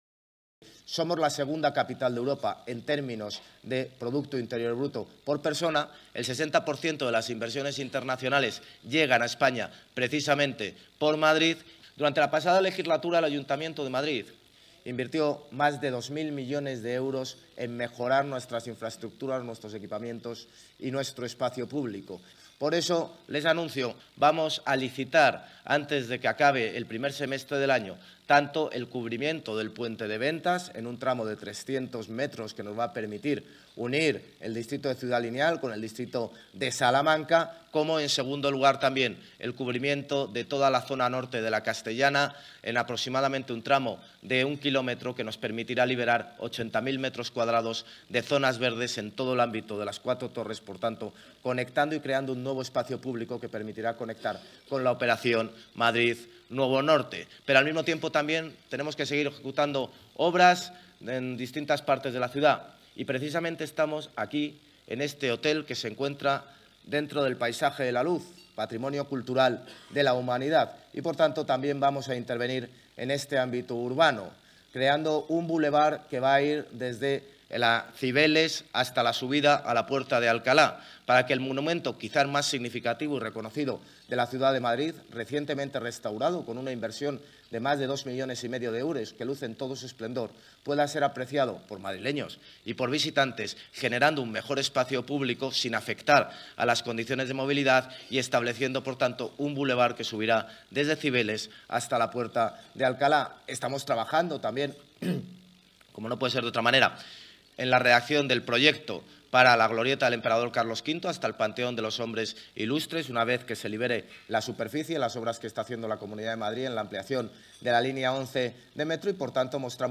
Durante su intervención en el desayuno informativo organizado por Nueva Economía Fórum
Nueva ventana:Declaraciones del alcalde, José Luis Martínez-Almeida